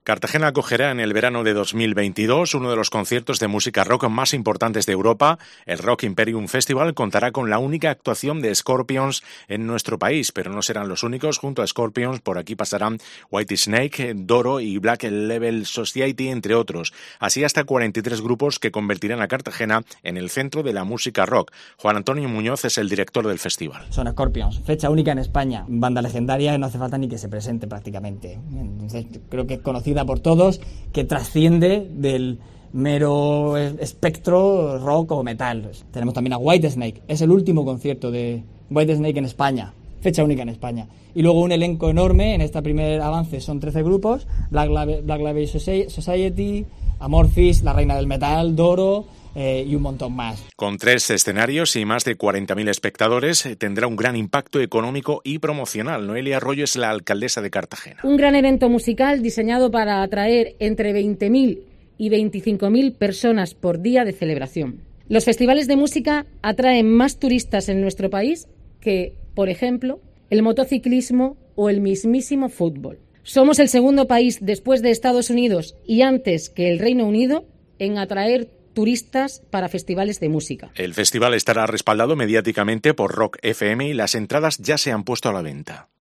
Entrevista Rock Imperium Festival